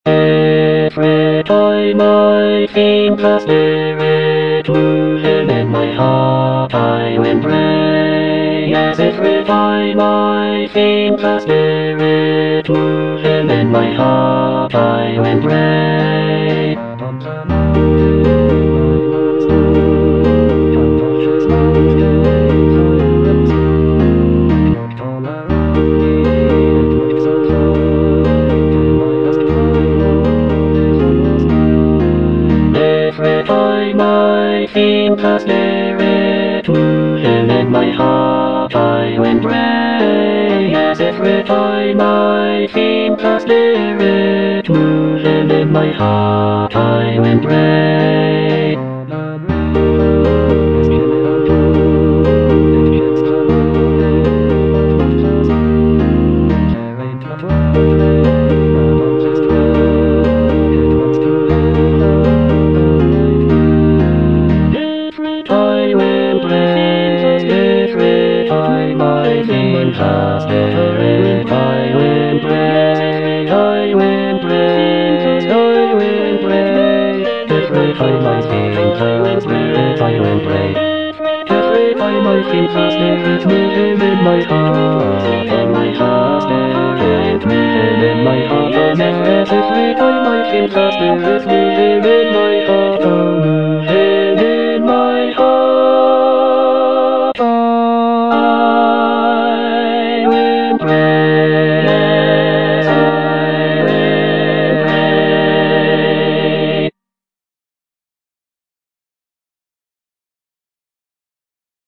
Bass II (Emphasised voice and other voices)
traditional African-American spiritual